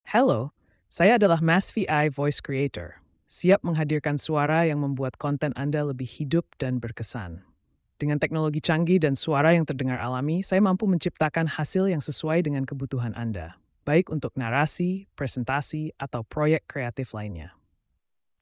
Ubah Kata Menjadi Suara Profesional dengan AI Voice Creator
Suara Natural dan Realistis: Teknologi canggih kami memastikan suara yang dihasilkan terdengar seperti manusia.
AI Voice Alloy
oleh Maz-V AI Voice Creator